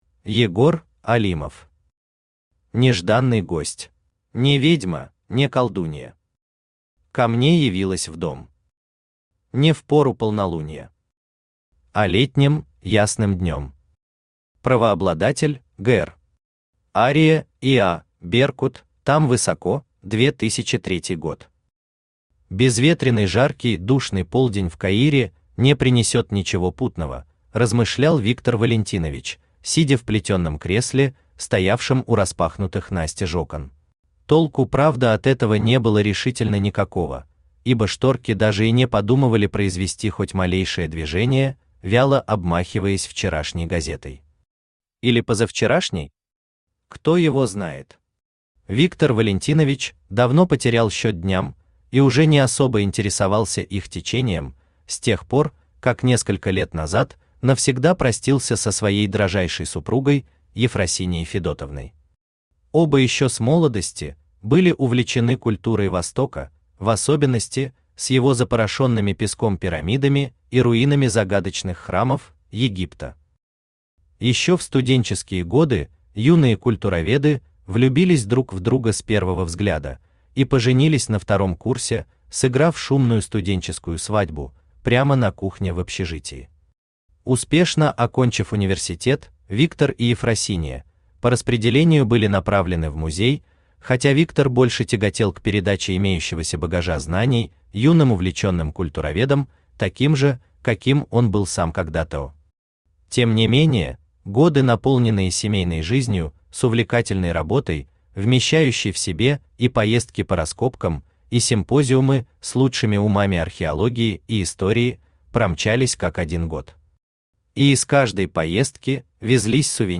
Aудиокнига Нежданный гость Автор Егор Алимов Читает аудиокнигу Авточтец ЛитРес.